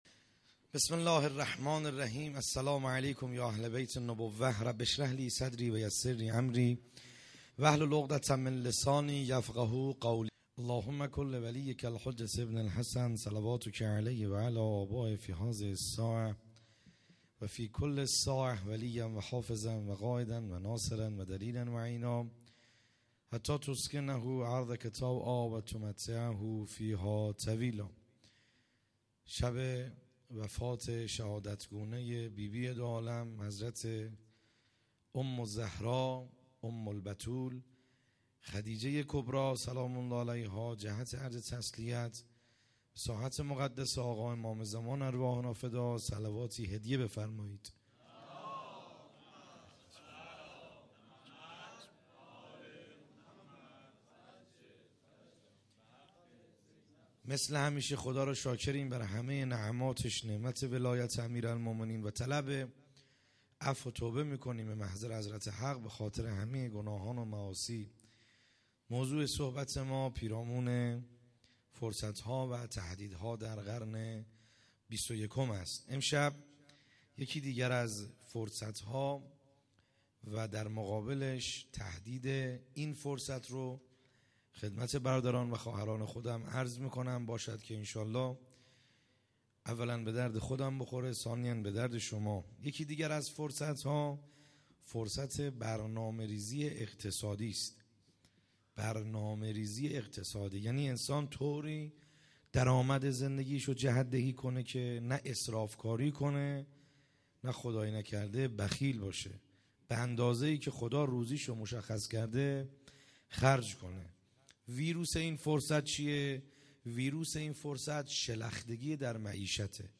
خیمه گاه - بیرق معظم محبین حضرت صاحب الزمان(عج) - سخنرانی | شب دهم